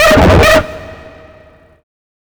OLDRAVE 8 -R.wav